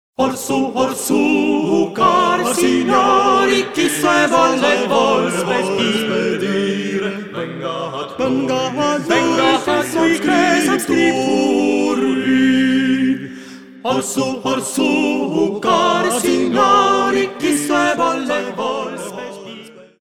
madrigals composed during the Renaissance
This is vocal music that belongs to the soul.